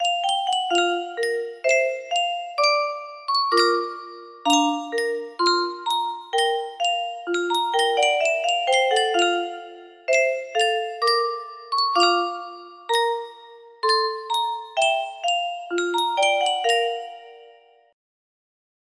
Yunsheng Music Box - Florida State Y631 music box melody
Full range 60